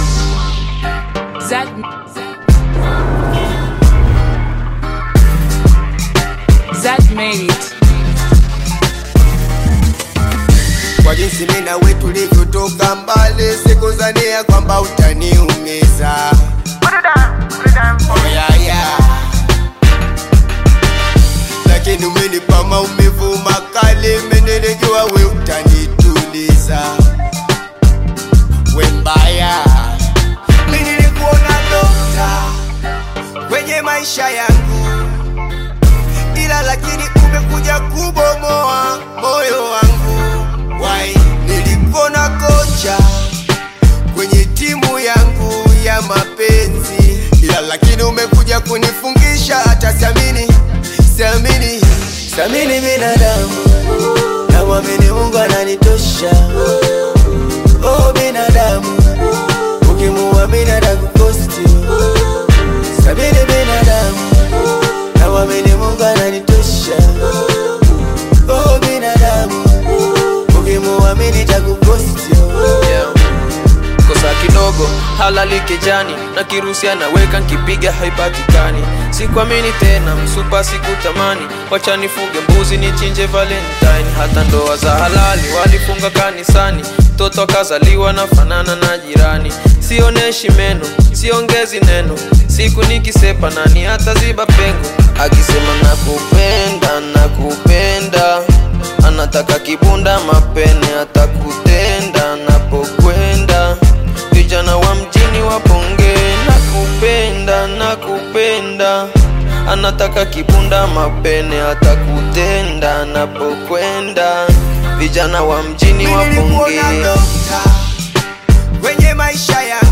Tanzanian Bongo Flava Duo singers, rappers, and songwriters
Bongo Flava